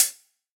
UHH_ElectroHatB_Hit-28.wav